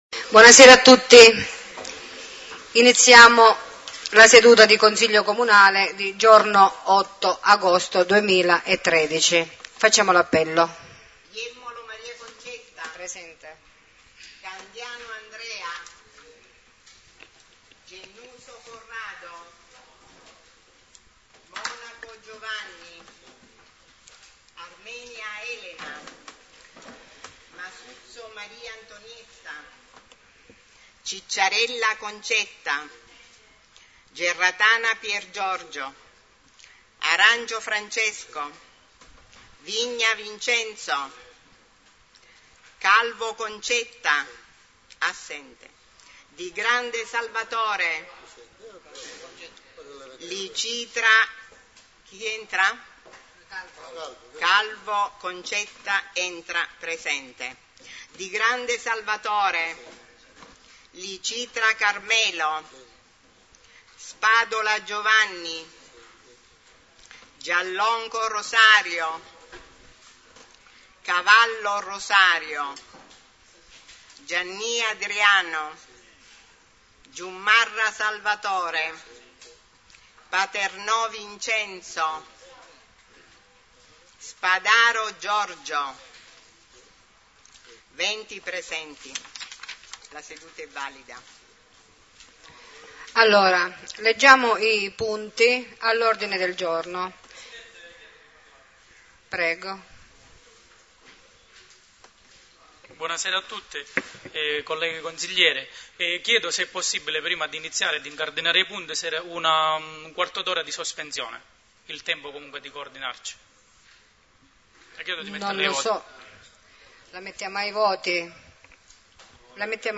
2 consiglio comunale 08.08.2013 – commissioni